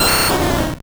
Cri d'Herbizarre dans Pokémon Or et Argent.